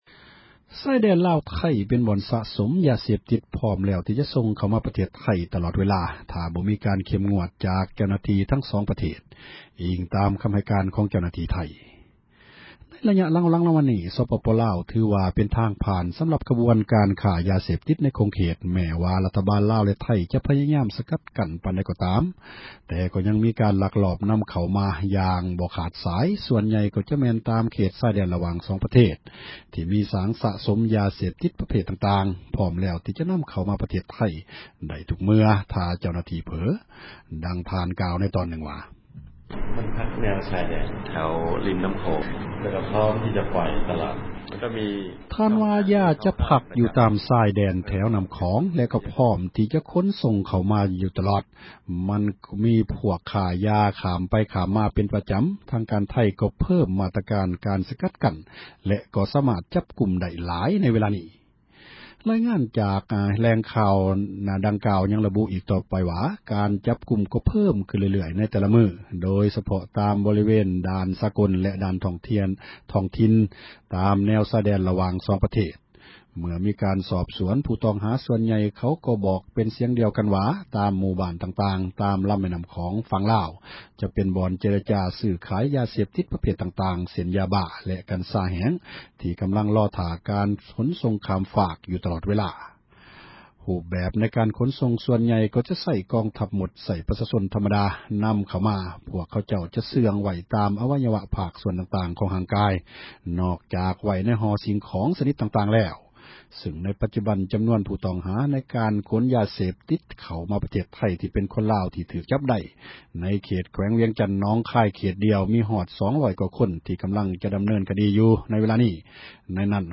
ເຊື່ອງ ຊື້ຂາຍ ຕໍ່ຣາຄາ ຢາເສບຕິດ ຕາມ ຊາຍແດນ ລາວ -ໄທ — ຂ່າວລາວ ວິທຍຸເອເຊັຽເສຣີ ພາສາລາວ